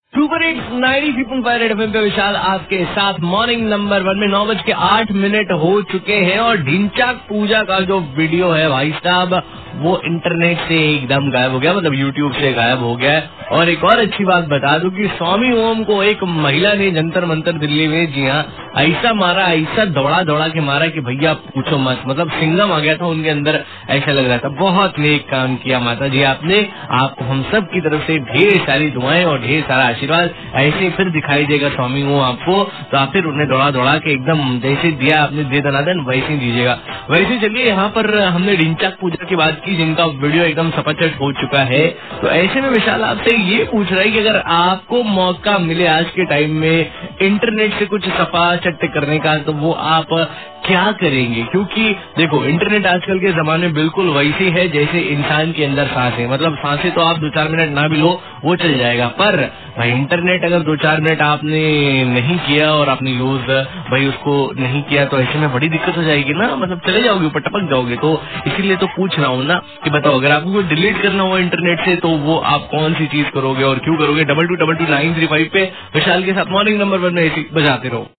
RJ TALKING ABOUT DHINCHAK POOJA